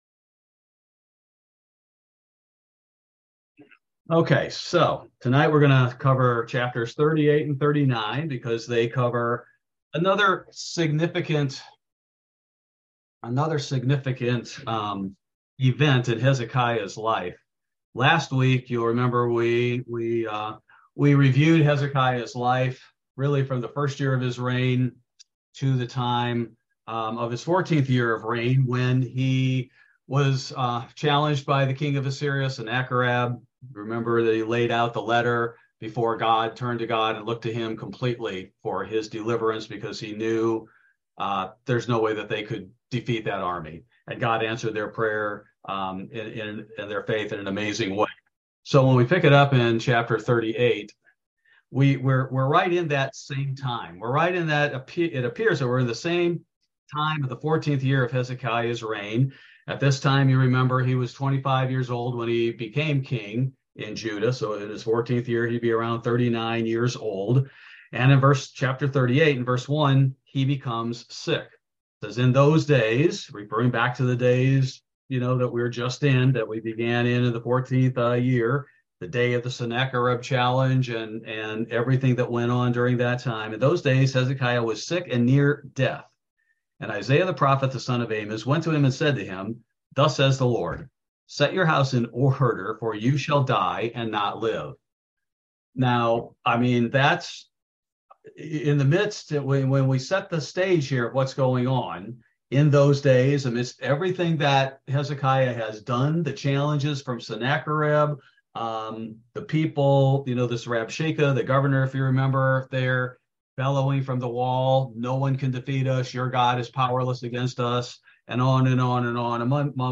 Bible Study: June 7, 2023